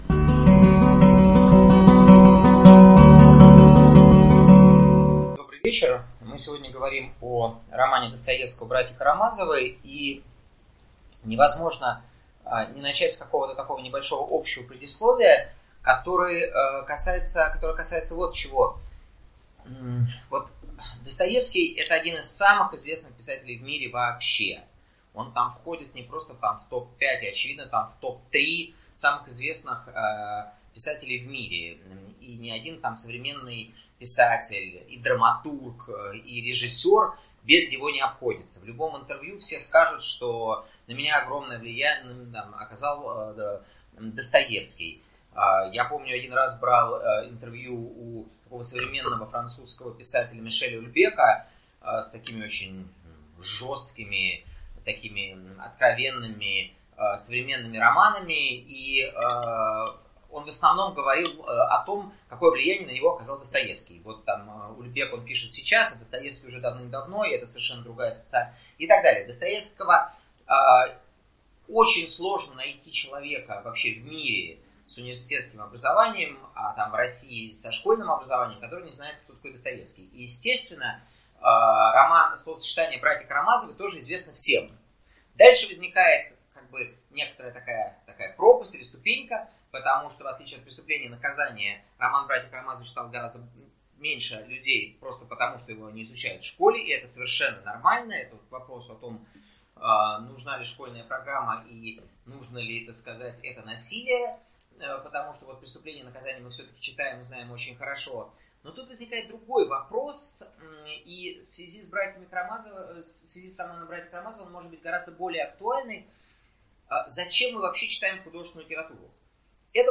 Аудиокнига Братья Карамазовы как идеальный детектив | Библиотека аудиокниг